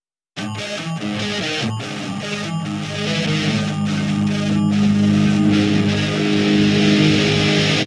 Дикий звук мелодии на электрогитаре